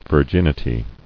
[vir·gin·i·ty]